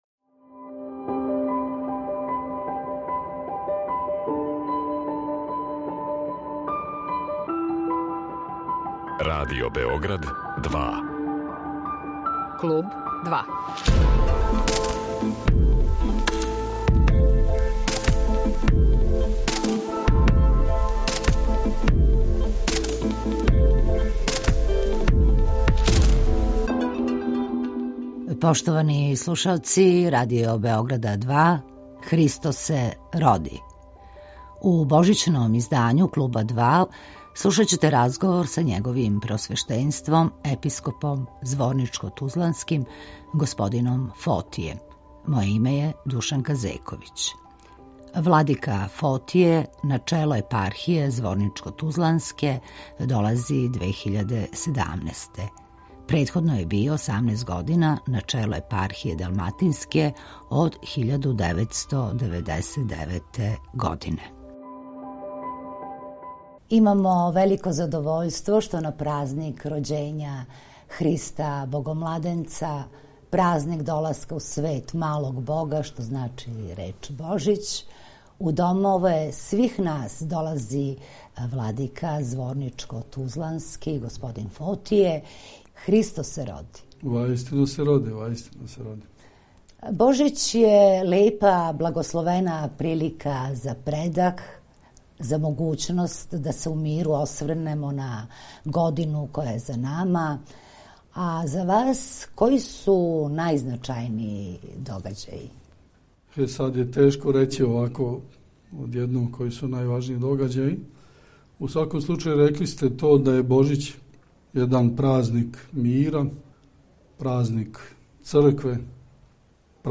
Епископ зворничко-тузлански Г. Фотије гост је емисије „Клуб 2'
Божићни интервју владике зворничко-тузланског Фотија